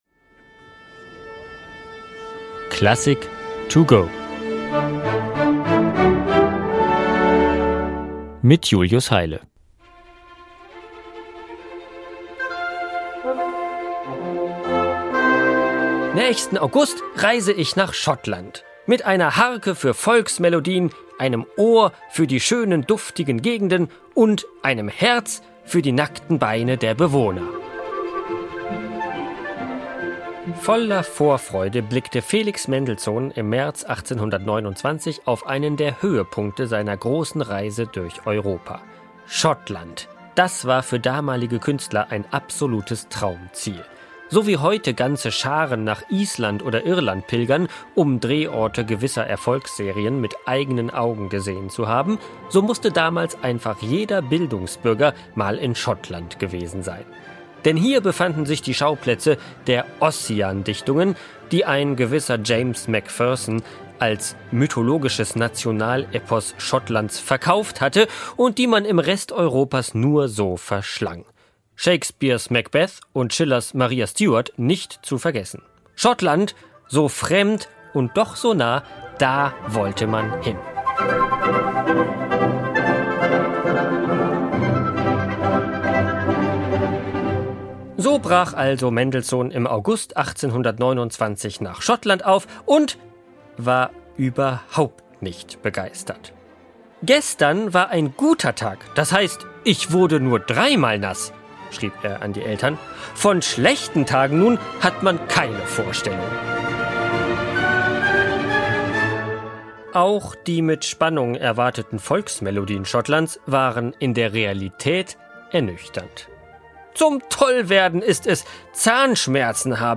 kurzen Werkeinführung für unterwegs